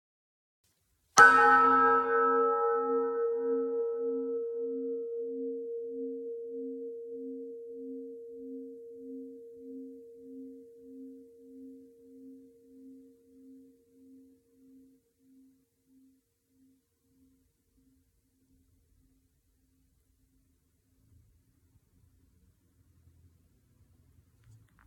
Die verschiedenen Teiltöne der Glocken hört man an allen Anschlagspunkten, jedoch in jeweils unterschiedlicher Intensität.
Anschlagpunkt e [451 KB]
glocke-brauweiler-e.mp3